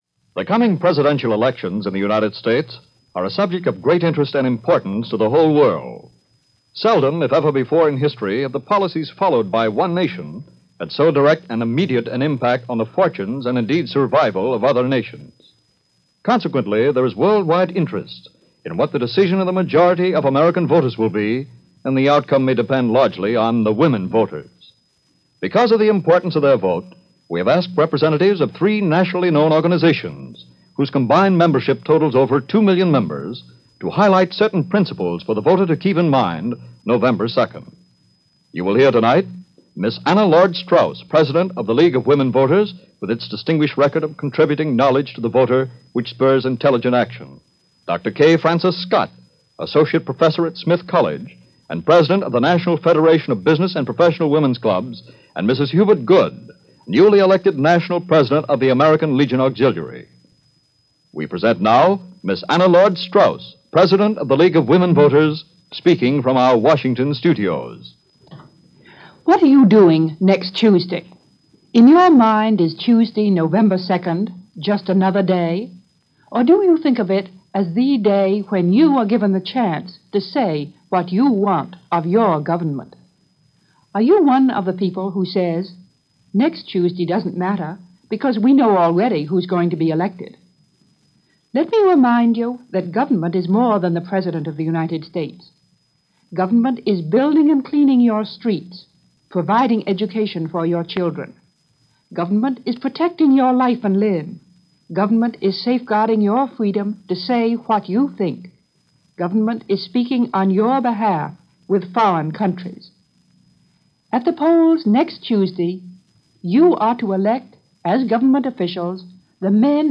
America Votes - 1948 - NBC Radio Special Program - America Votes: 1948, broadcast on October 30, 1948 - Past Daily Reference Room